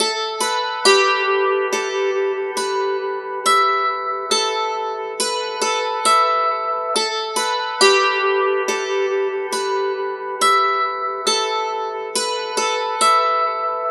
Dulcimer03_69_G.wav